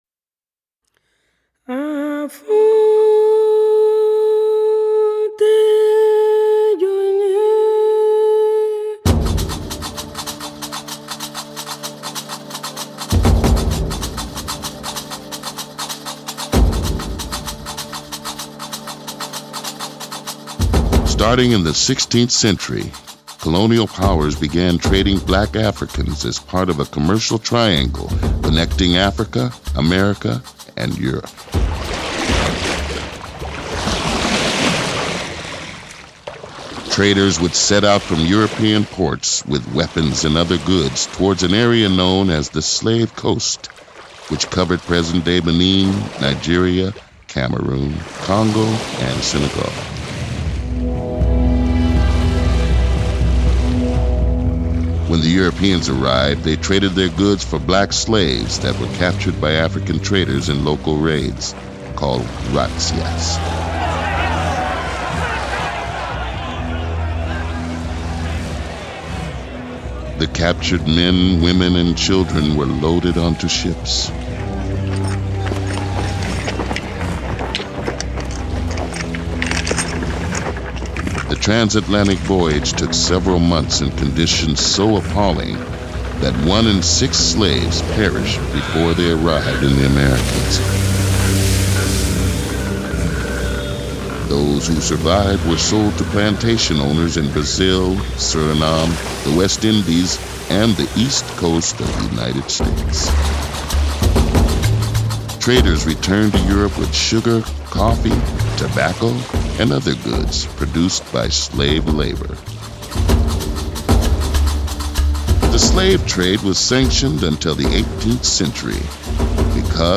Mystic Percussion – (D. Malherbe – Kosinus KOS 44
Narrator:Blu Mankuma